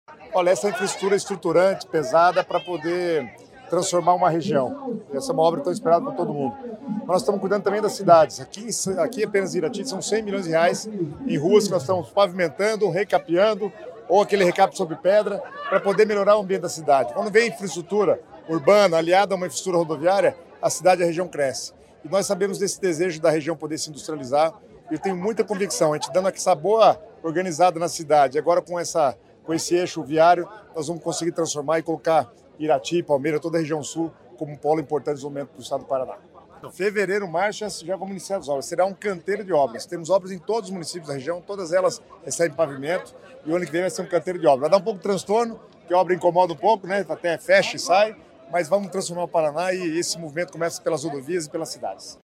Sonora do secretário das Cidades, Guto Silva, sobre o início da duplicação da BR-277 em Palmeira e Irati